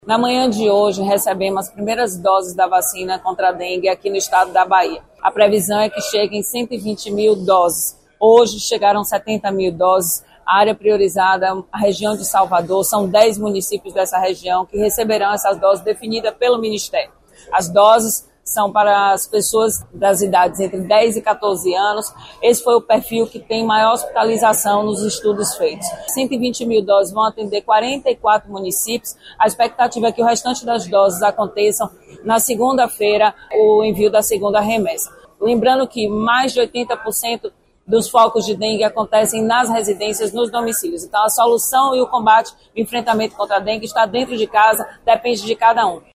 🎙 Secretária Roberta Santana
SONORA_ROBERTA_SANTANA_VACINA_DENGUE.mp3